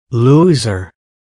Звуки лузеров
На этой странице собраны забавные звуки лузеров – от провальных фраз до эпичных неудач.